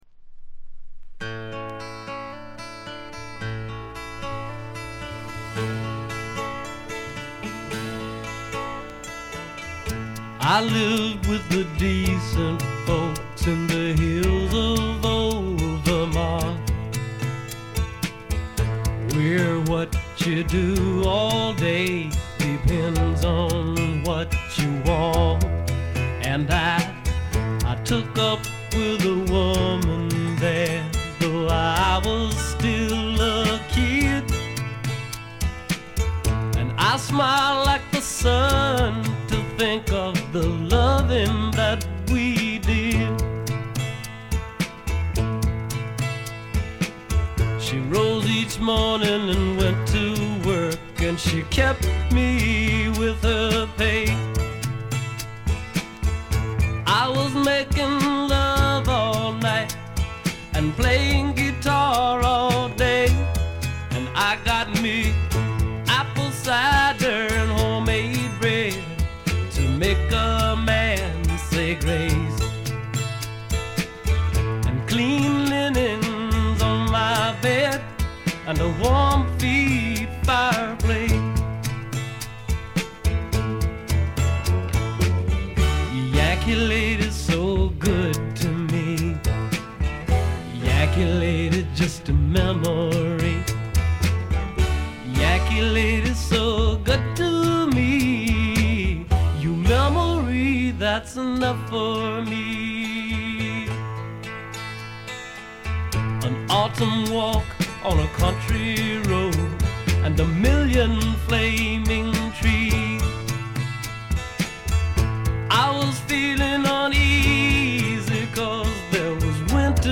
部分試聴ですが軽いチリプチ程度。
ロマンチシズムをたたえながらもメランコリックになり過ぎない、硬質な質感に貫かれたとても素敵なアルバム。
試聴曲は現品からの取り込み音源です。